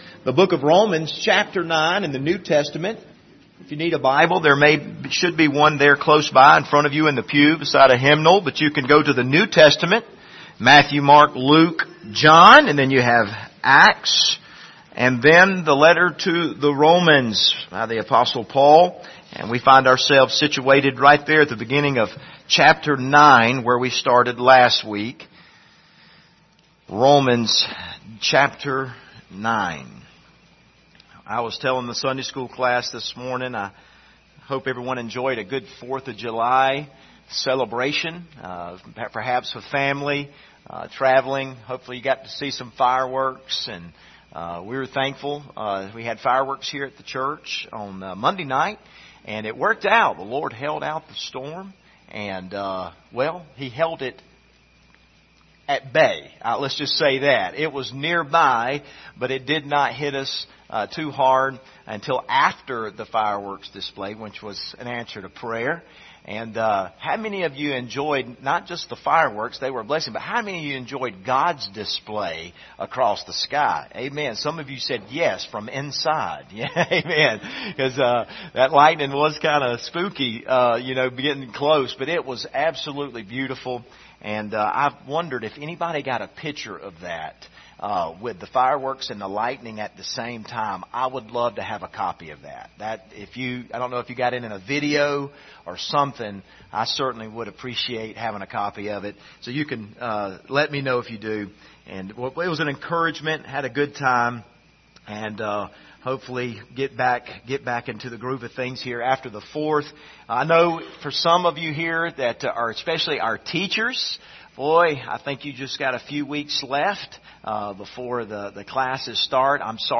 Passage: Romans 9:4-5 Service Type: Sunday Morning